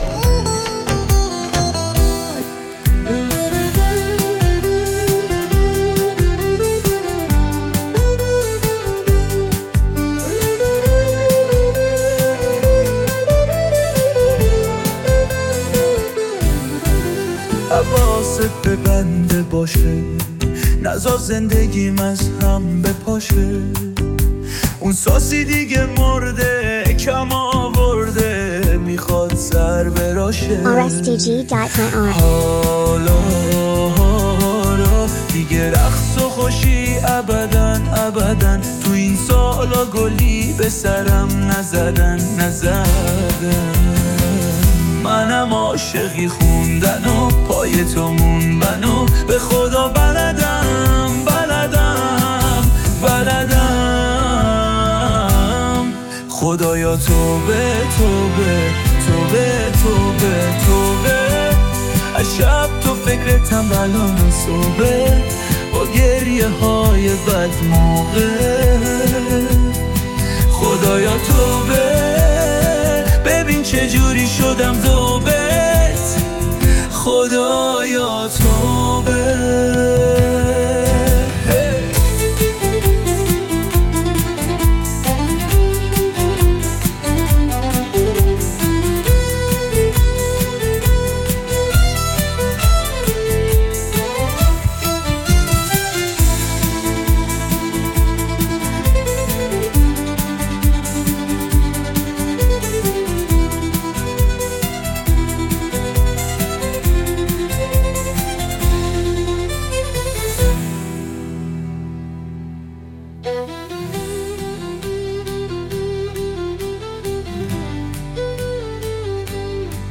کاور خاص